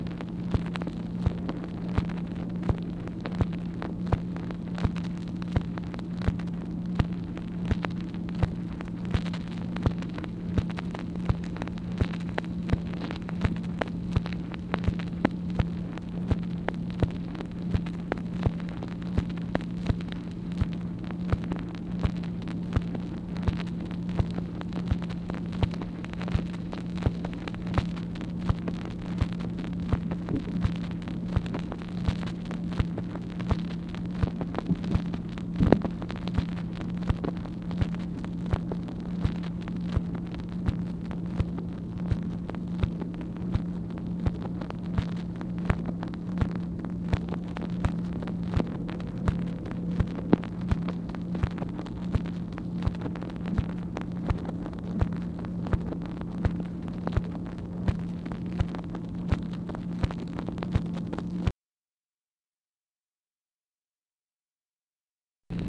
MACHINE NOISE, April 1, 1964
Secret White House Tapes | Lyndon B. Johnson Presidency